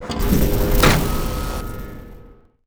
shieldsoff.wav